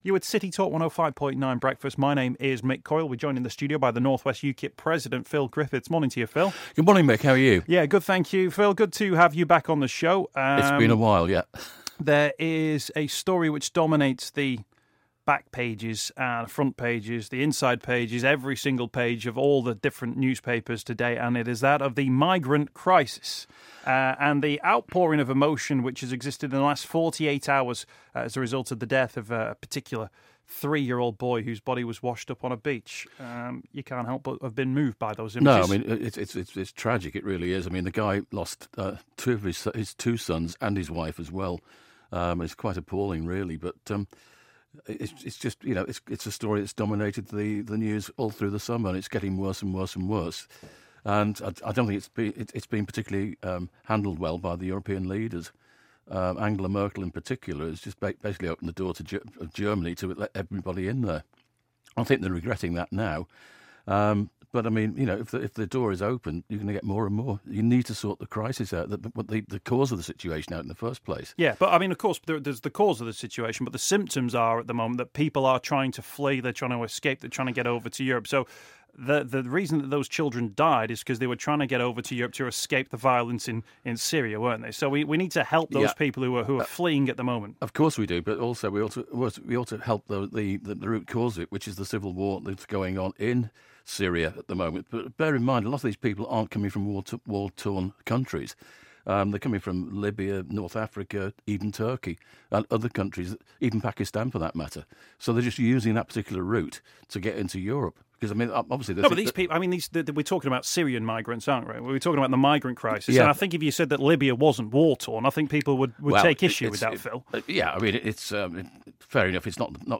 As heard on CityTalk 105.9 at 7.45am on Friday 4th September 2015.